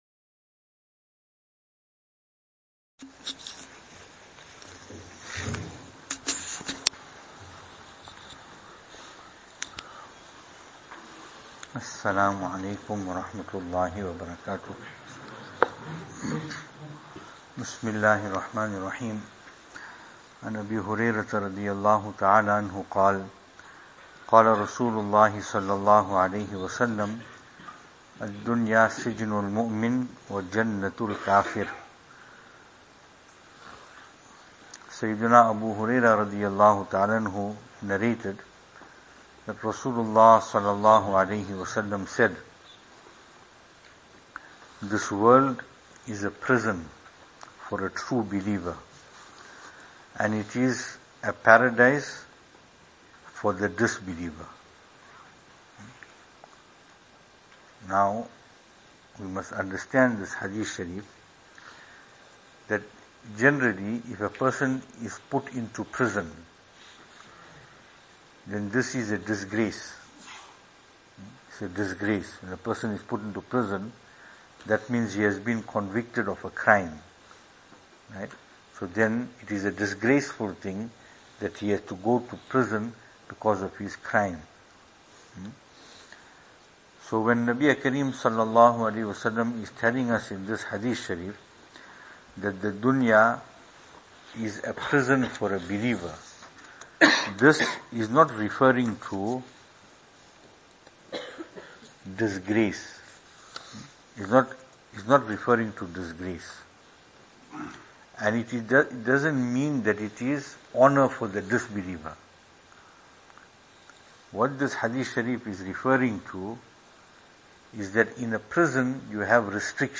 After Taraweeh Advices 5th Night